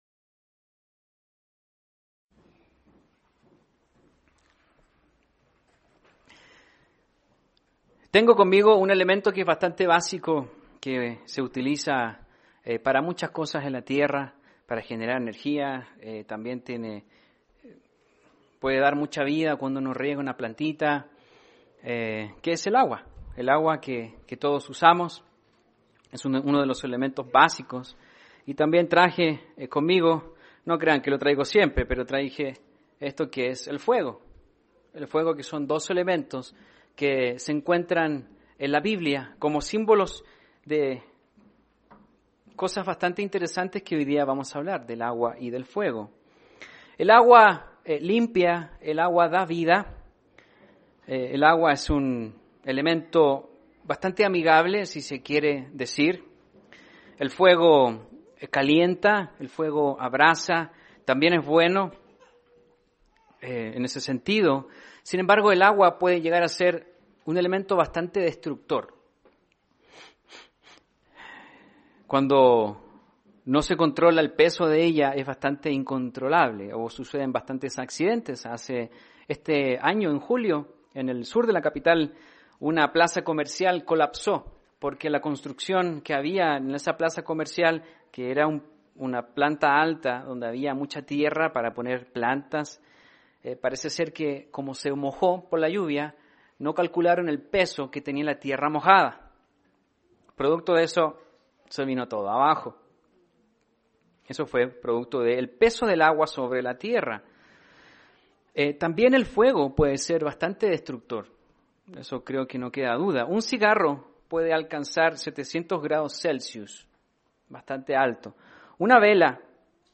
Agua y fuego son herramientas con las que se ha dado y dará rumbo a la humanidad, castigándole y dándole vida. Mensaje entregado el 15 de septiembre de 2018.